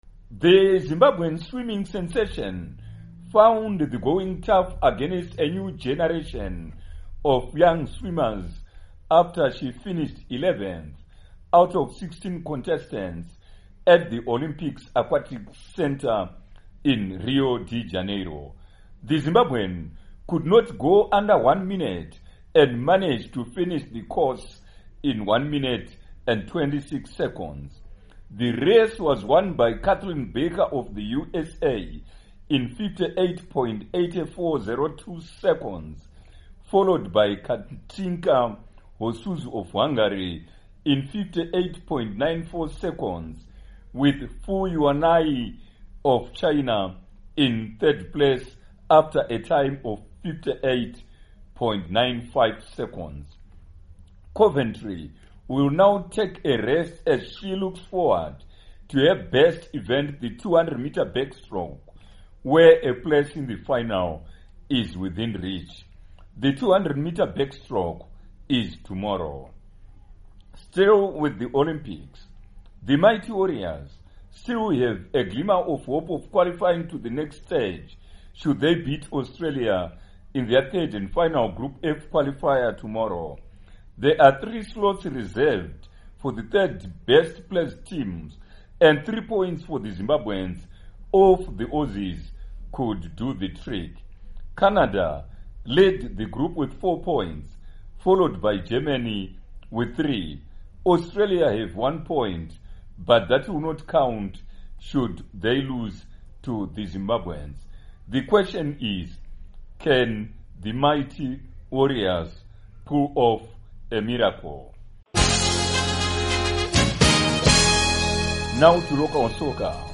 Report on Coventry